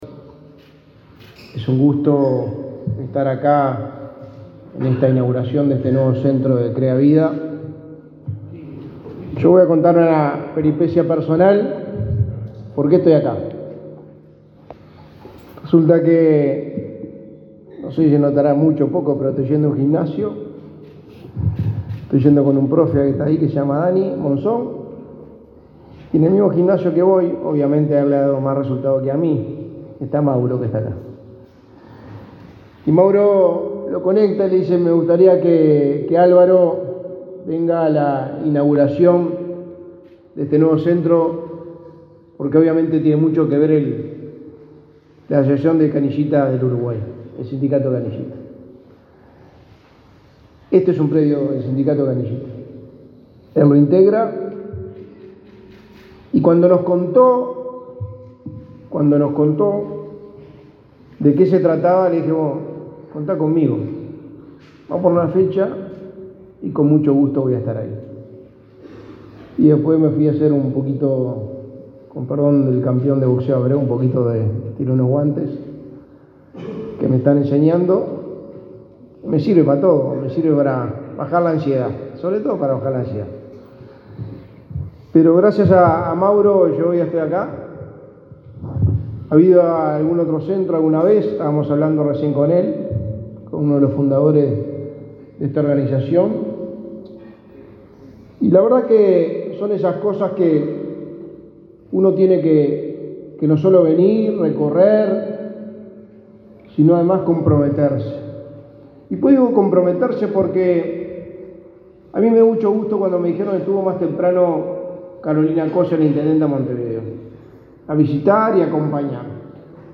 Palabras del secretario de Presidencia, Álvaro Delgado
Palabras del secretario de Presidencia, Álvaro Delgado 08/11/2023 Compartir Facebook X Copiar enlace WhatsApp LinkedIn El secretario de Presidencia, Álvaro Delgado, participó, este miércoles 8 en el barrio Malvín de Montevideo, en la inauguración de un centro de tratamiento y rehabilitación para personas con trastorno por consumo de sustancias.